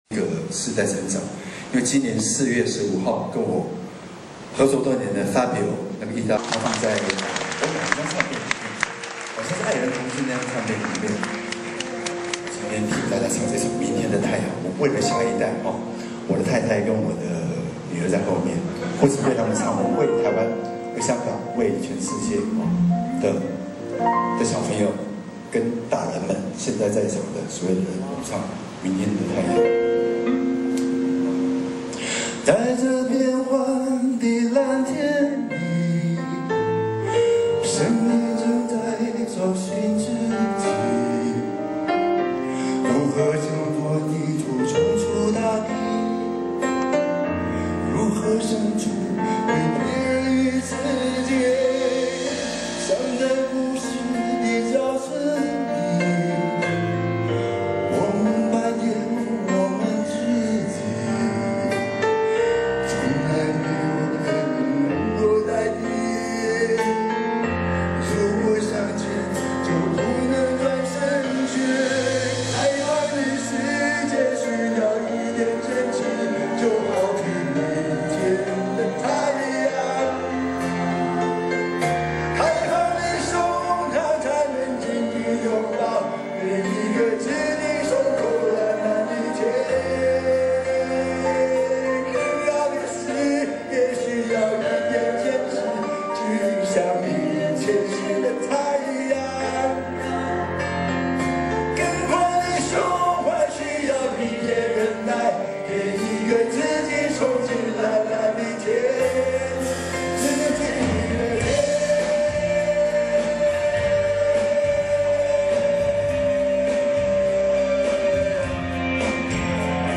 去聽了這場演唱會 最後拍了兩首安首曲 喜歡的網友請慢慢看鳥